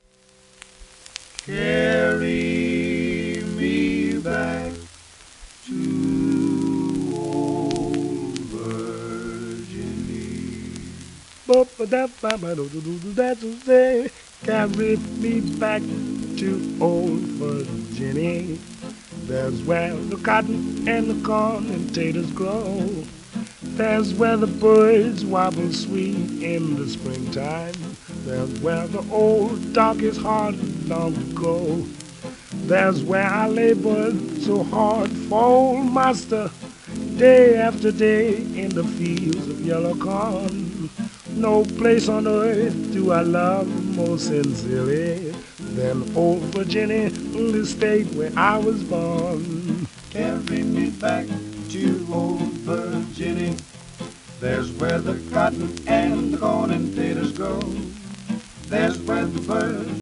1937年録音